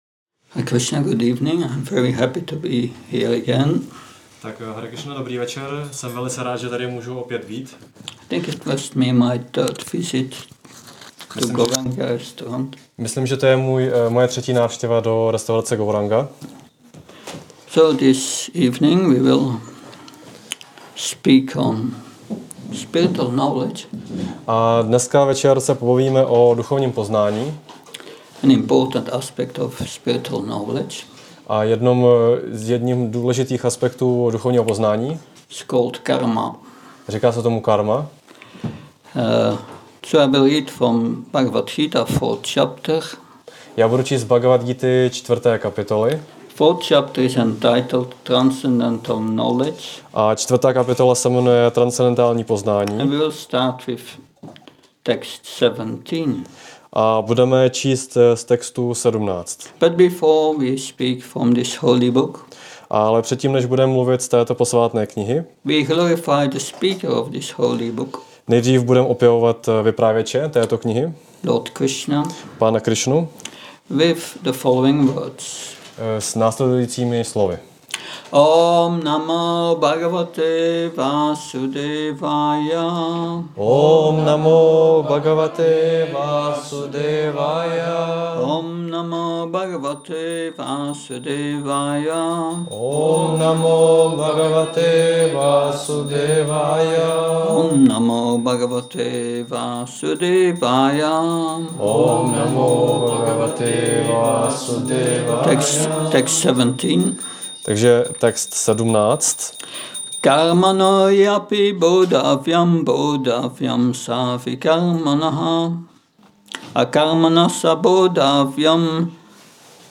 Přednáška BG-4.17